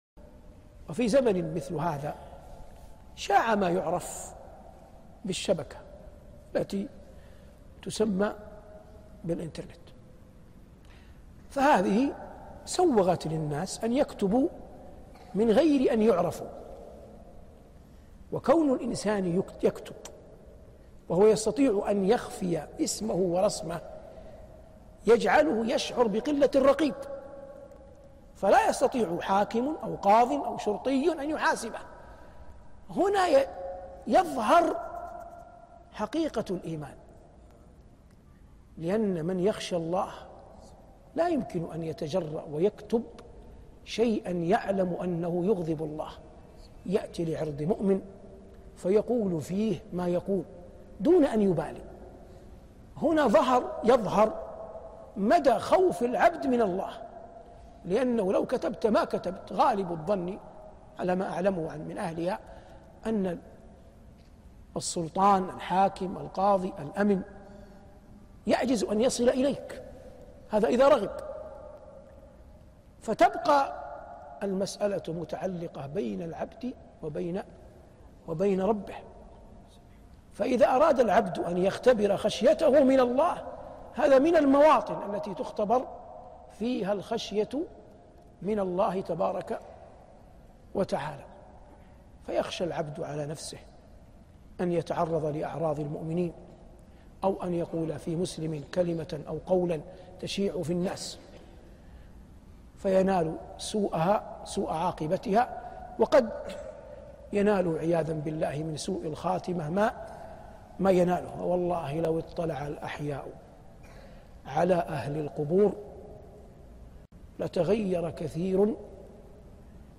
Short lecture by Shaykh Salih Al Maghamsi, who is the imam of Masjid al Quba in Medina, Saudi Arabia.